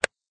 face_snap.ogg